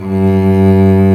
Index of /90_sSampleCDs/Roland - String Master Series/STR_Vc Arco Solo/STR_Vc Arco f vb